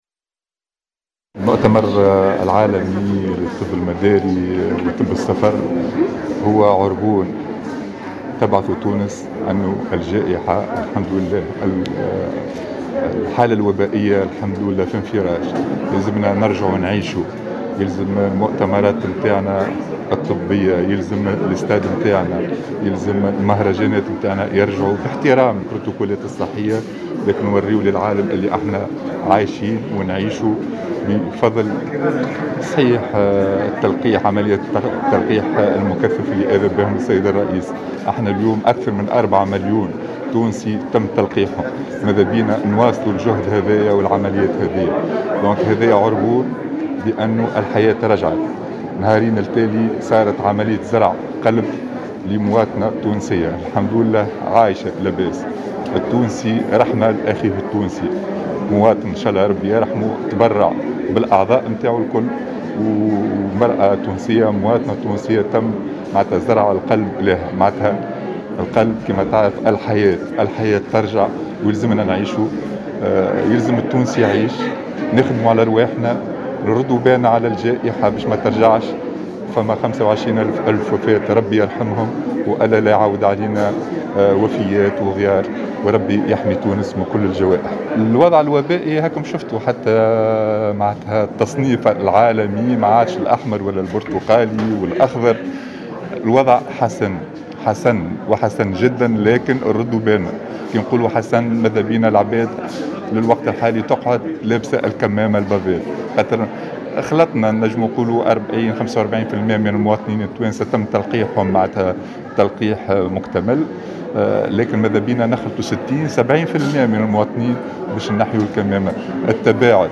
أكد المكلف بتسيير وزارة الصحة علي المرابط في تصريح إعلامي خلال زيارة أداها اليوم الجمعة إلى سوسة، تحسن الوضع الوبائي في البلاد، وهو ما يعكسه التصنيف العالمي لتونس فيما يتعلق بتفشي فيروس كورونا، مع تقدم عملية التلقيح في البلاد ووصولها إلى نحو 45% من التونسيين الذين أتموا عملية التطعيم.